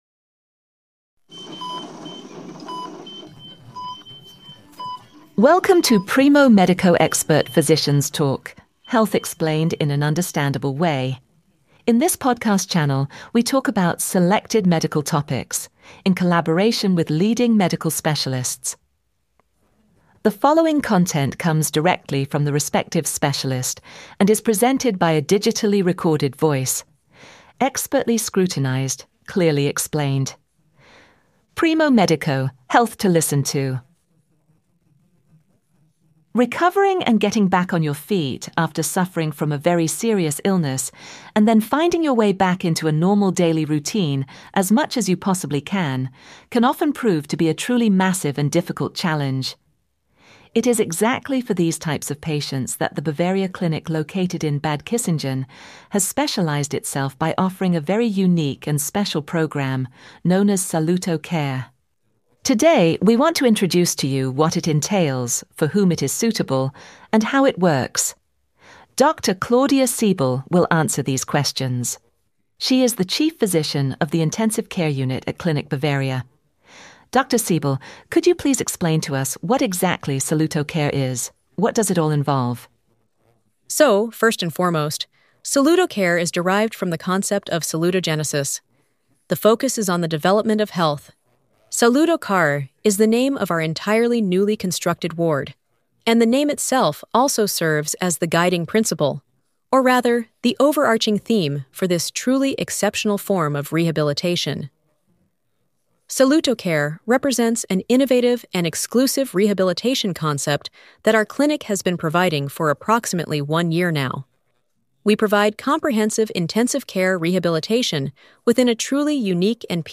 PRIMO MEDICO Specialist Talk Podcast on therapy for complex